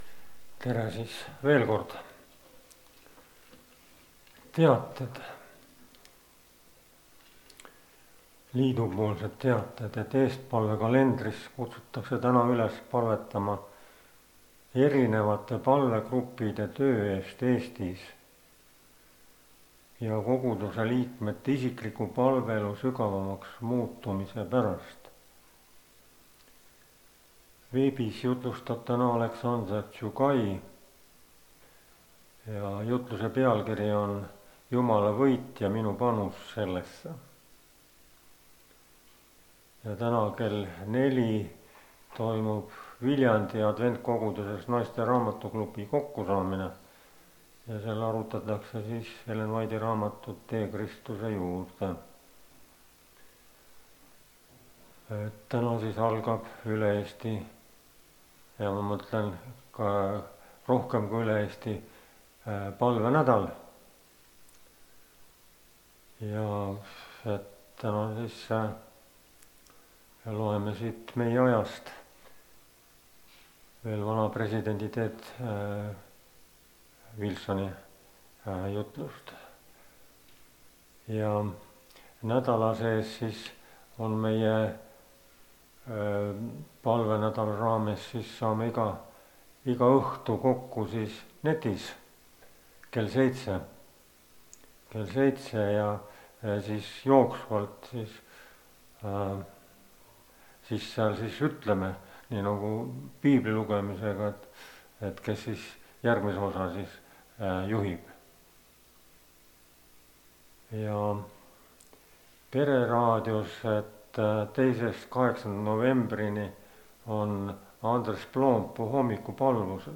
Palvenädala loeng (Rakveres)
kategooria Audio / Koosolekute helisalvestused